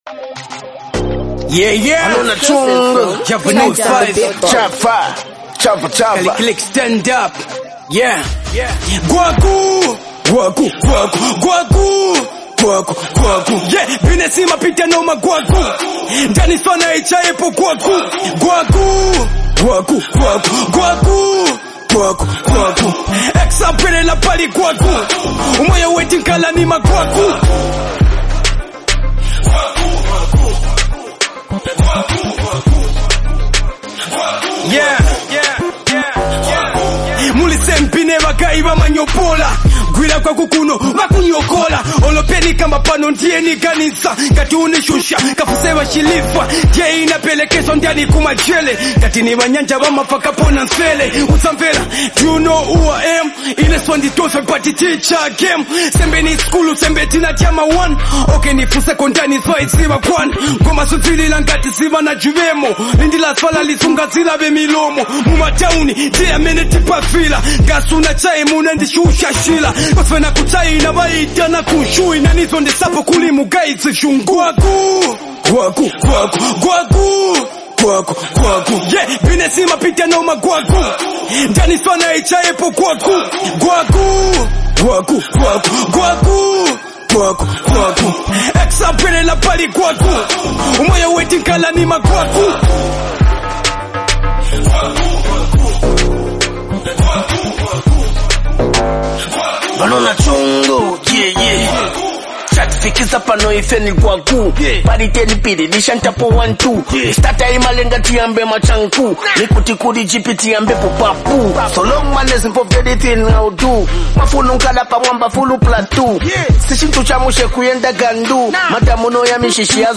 hard-hitting street anthem
signature gritty flow and street-smart lyricism